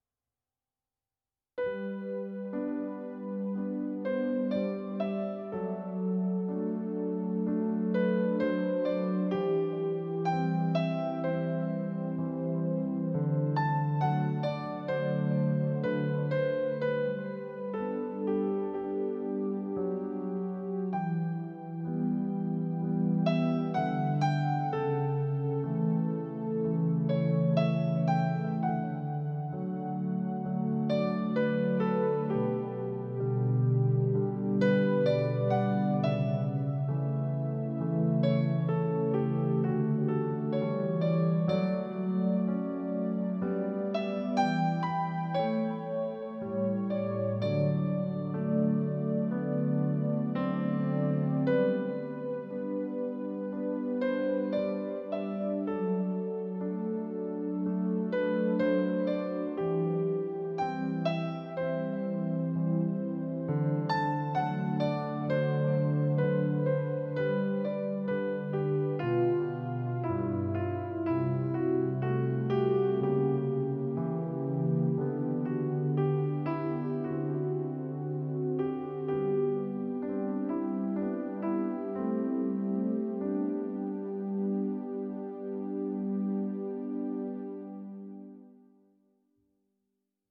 This music selection can be used for any of these parts of a wedding ceremony:  prelude, processional, bridal entrance, interlude (background music for exchanging of the vows, unity candle, sand ceremony, catholic traditions, etc.), recessional and postlude.